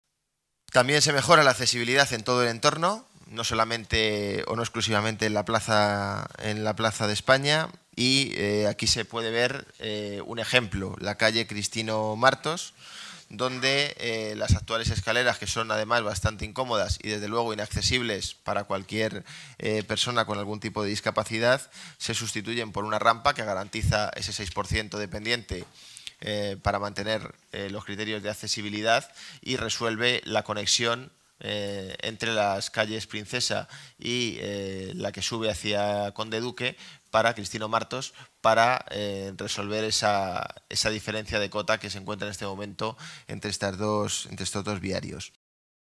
El delegado del Área de Desarrollo Urbano Sostenible explica el proyecto